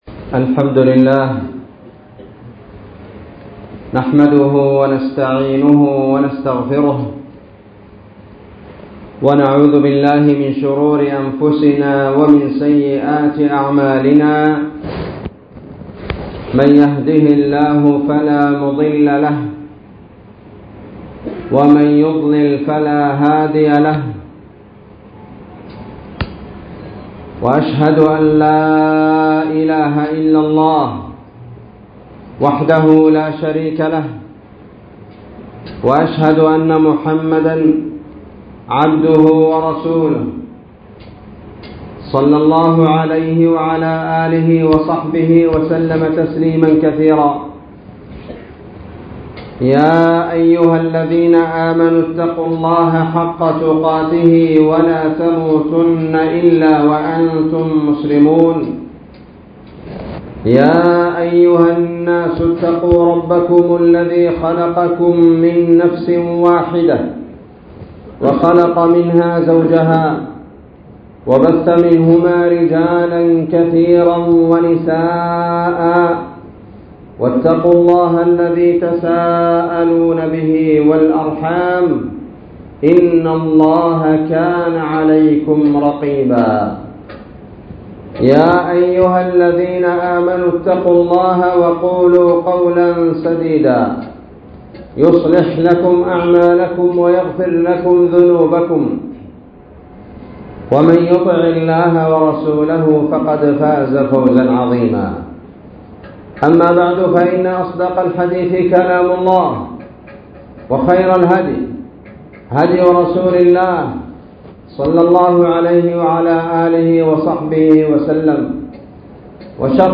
خطبة قيمة
مسجد عمر كبير- بربرة - بلاد أرض الصومال